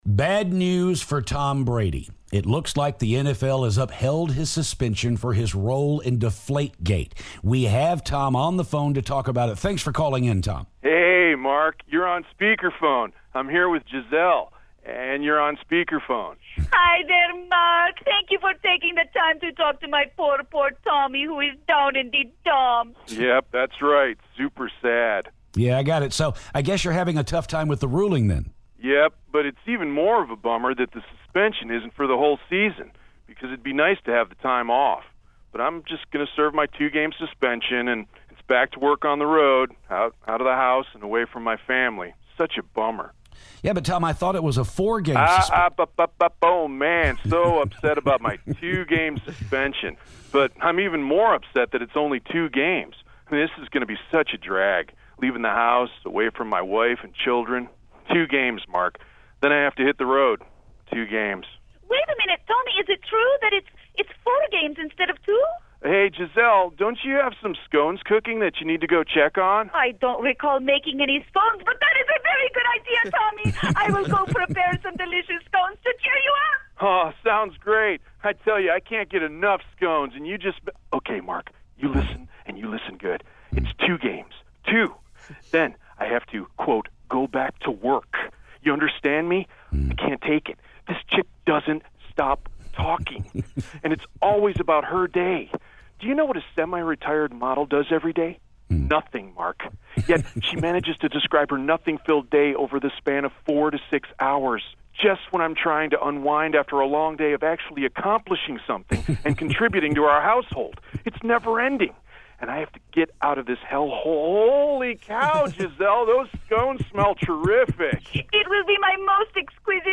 Tom Brady calls to talk about his suspension.